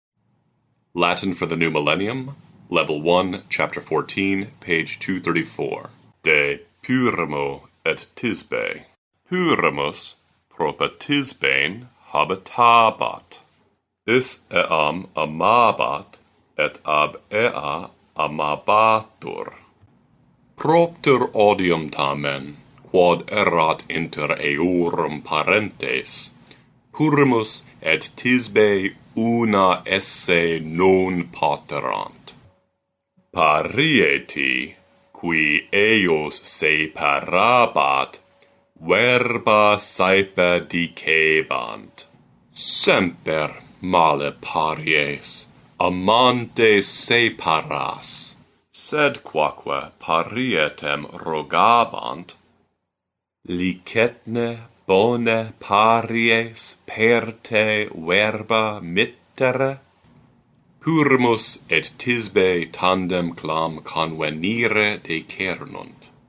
provide a professionally recorded reading in the restored classical pronunciation of Latin.